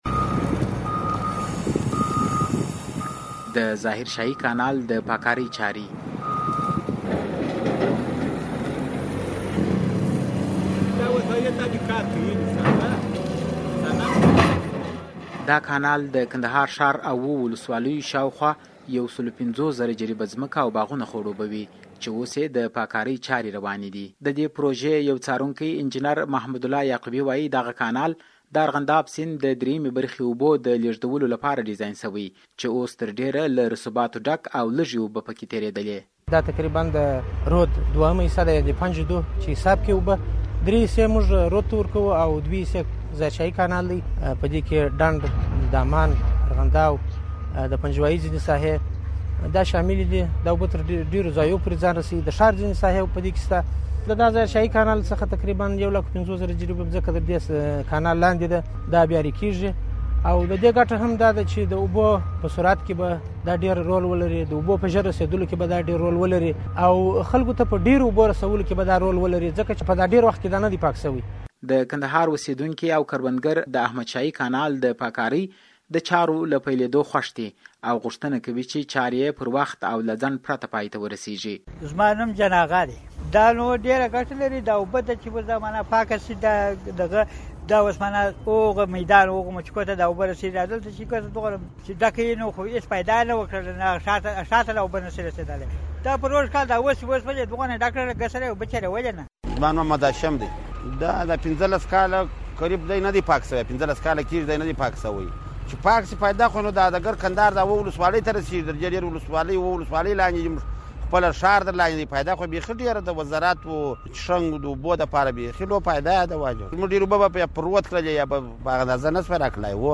د کندهار راپور